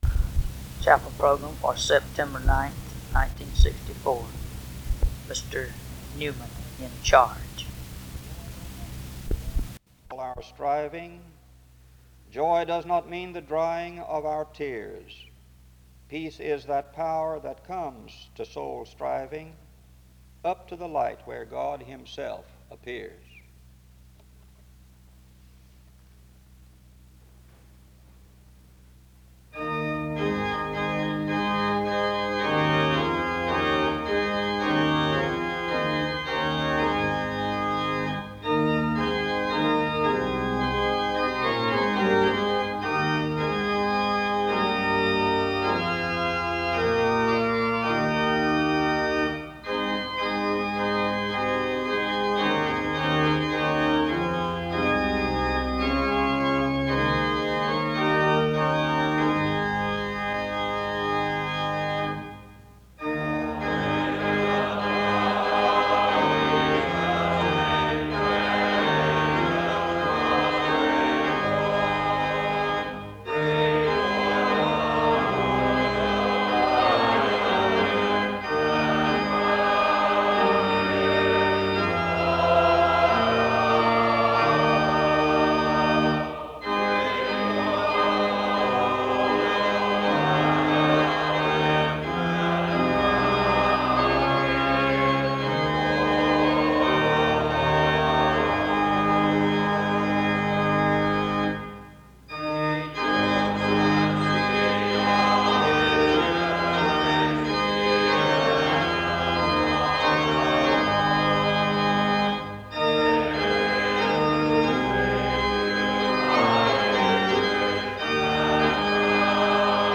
Music plays from 0:28-3:49. There is a responsive reading from 3:55-5:42. A prayer is made from 5:46-7:46. There is special music from 7:55-11:49.
His source text was the entirety of 1 Corinthians 8. A closing hymn is played from 26:01-27:48.